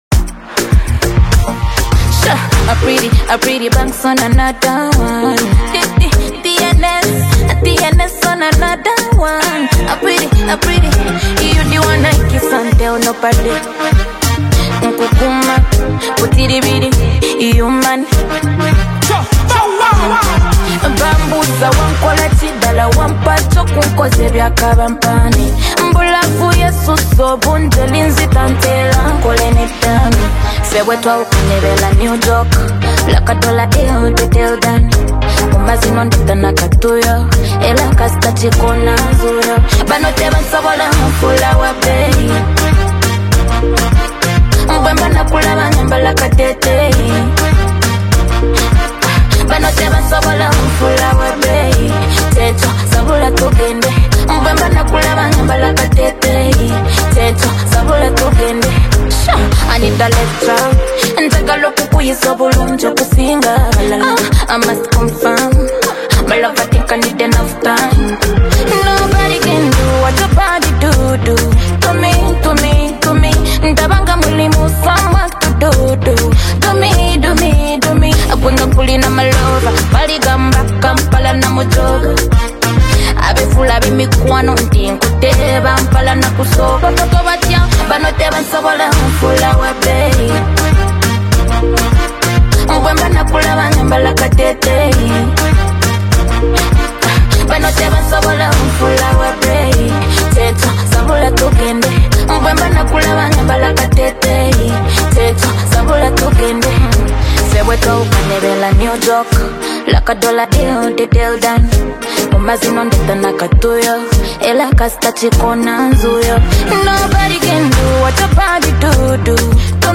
is an emotional song
Through heartfelt lyrics and a touching melody